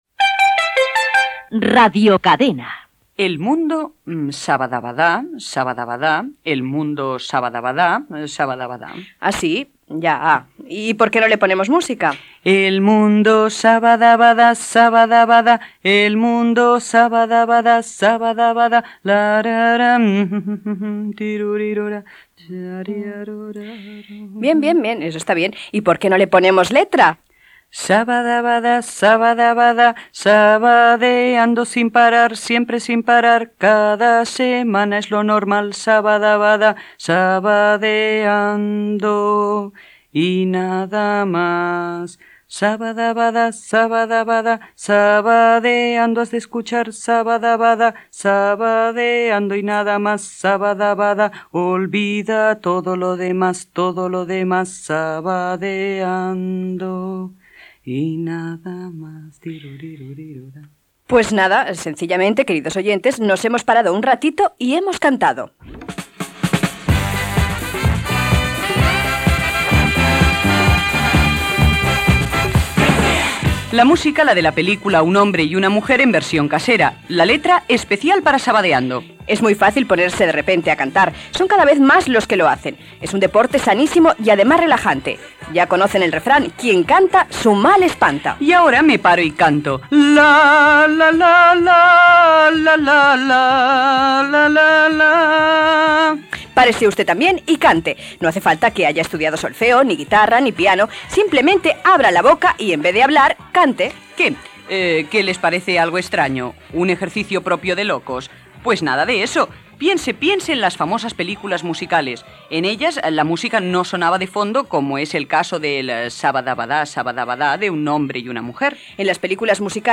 Indicatiu de l'emissora, cançó improvisada del programa, la importància de cantar, tothom ho pot fer, joc de paraules
Entreteniment